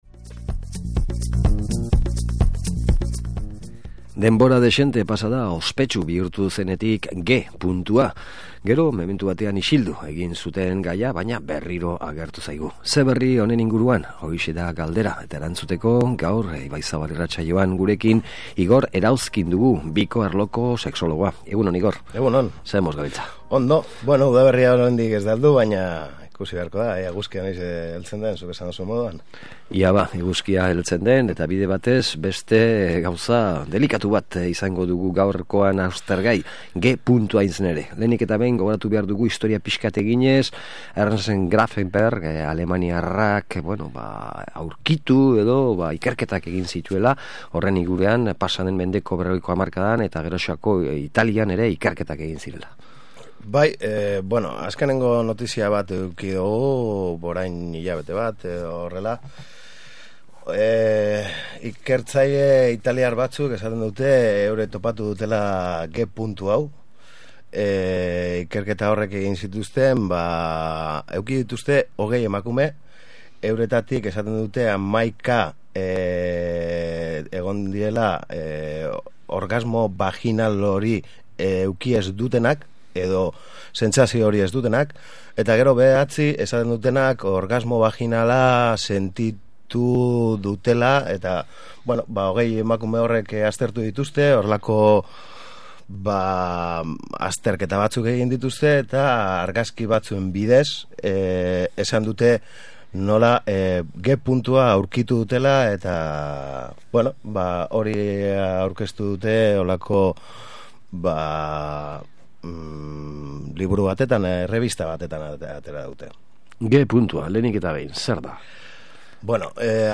SOLASALDIA: G Puntua